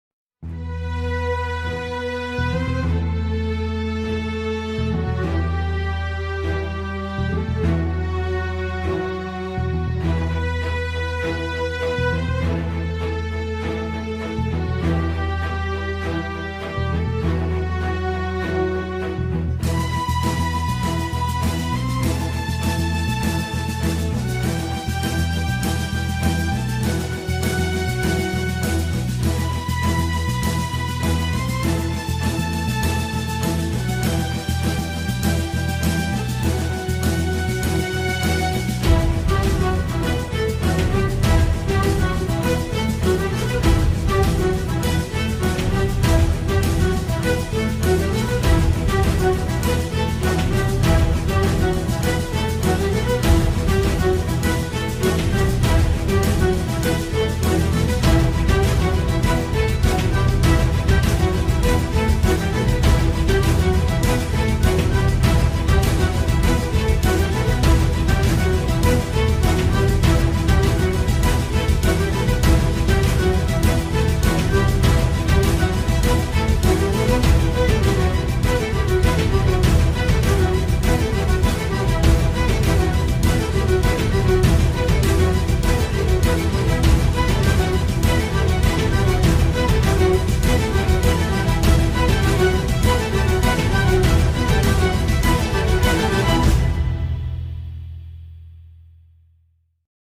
tema dizi müziği, heyecan gerilim aksiyon fon müziği.